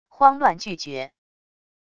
慌乱拒绝wav音频